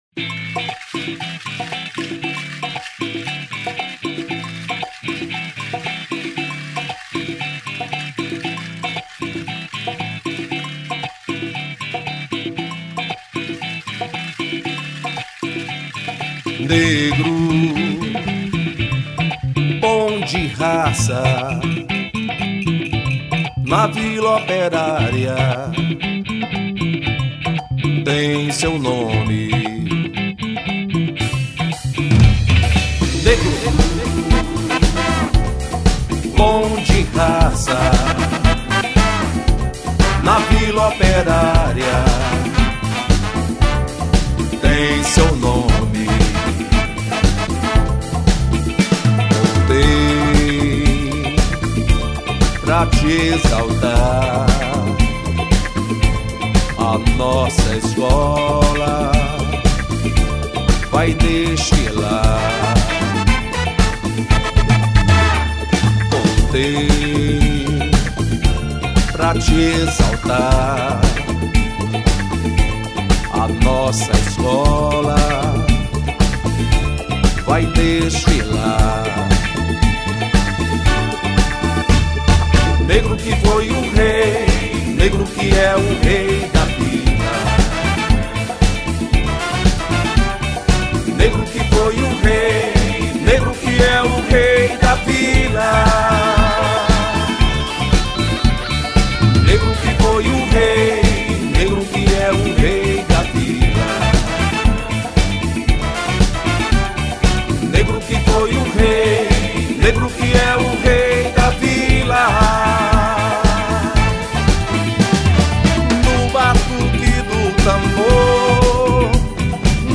49   05:44:00   Faixa:     Mpb